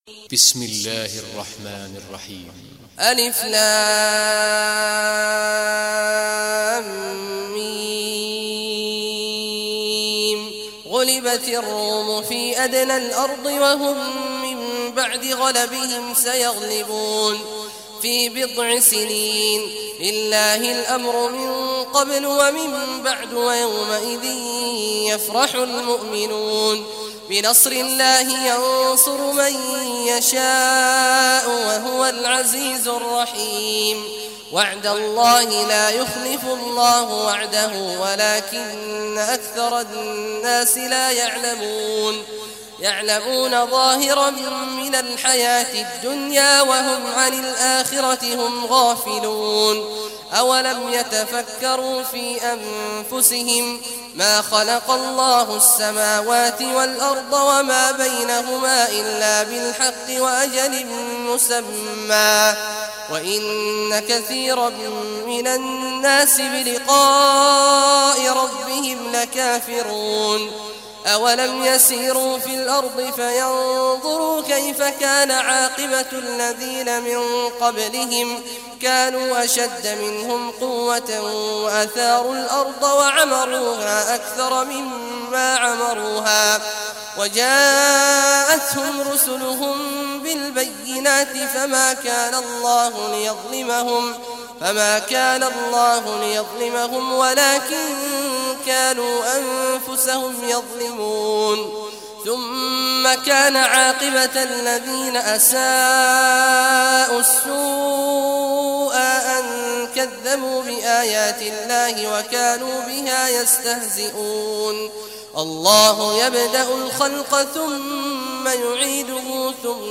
Surah Ar-Rum Recitation by Sheikh Awad al Juhany
Surah Ar-Rum, listen or play online mp3 tilawat / recitation in Arabic in the beautiful voic of Sheikh Abdullah Awad al Juhany.